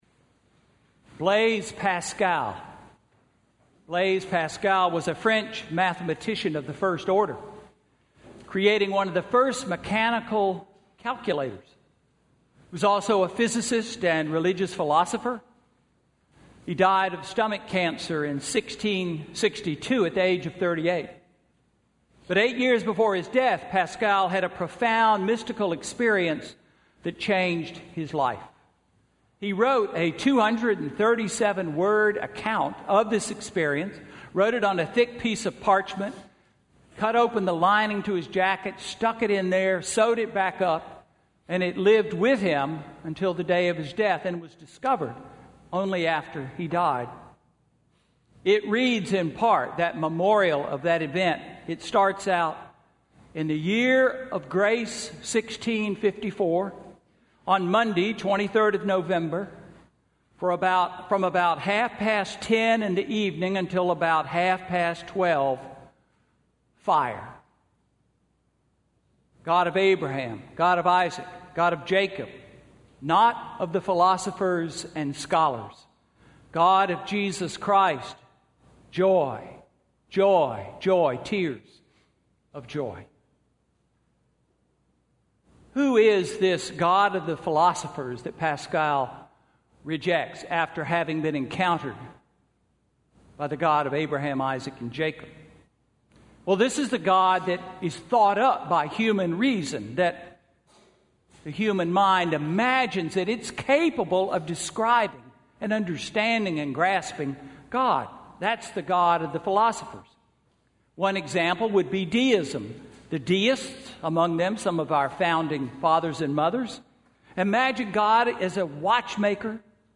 Sermon–December 13, 2015